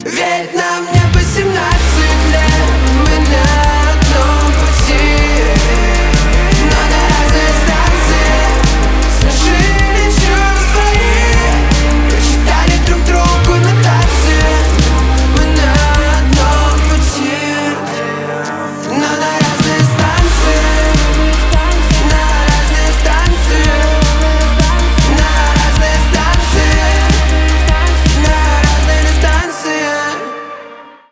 поп
гитара , барабаны , грустные